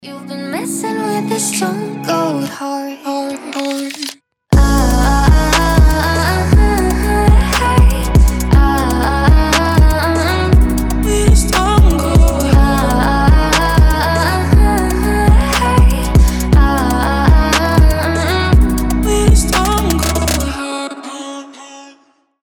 • Качество: 320, Stereo
мелодичные
спокойные
медленные
красивый женский голос